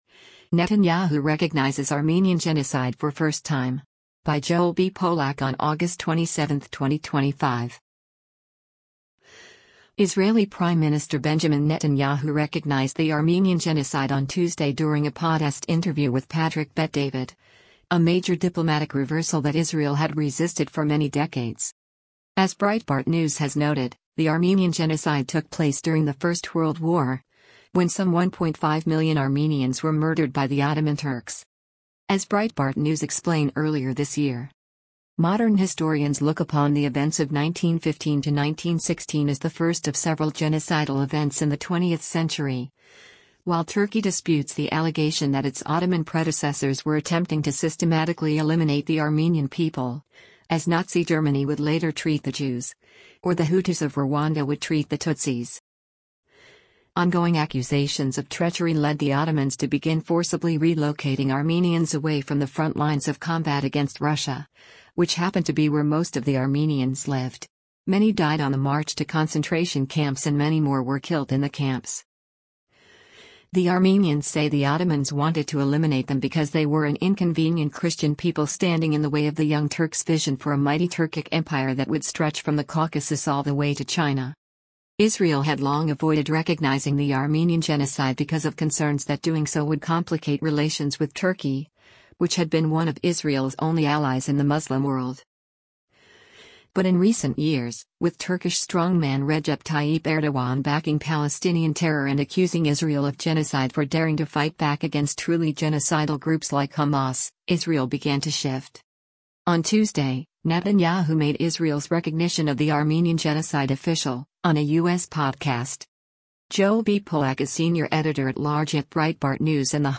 Israeli Prime Minister Benjamin Netanyahu recognized the Armenian Genocide on Tuesday during a podast interview with Patrick Bet-David — a major diplomatic reversal that Israel had resisted for many decades.